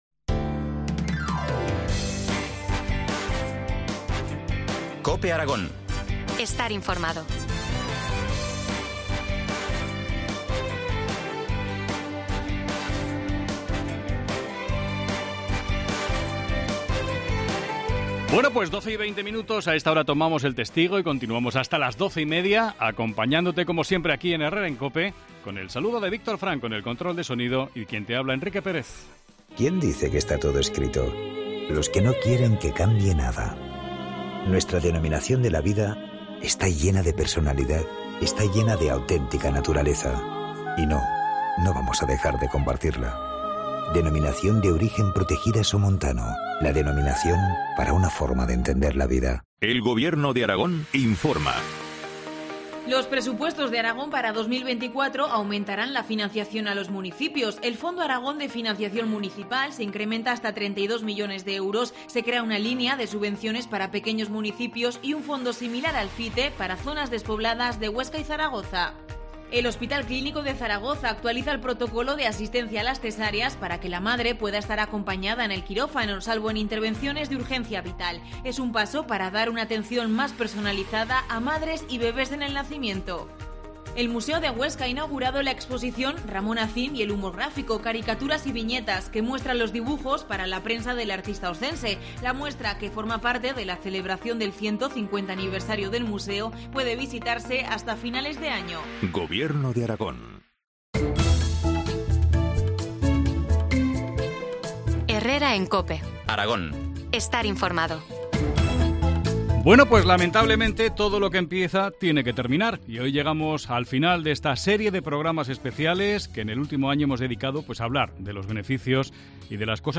Entrevista del día en COPE Aragón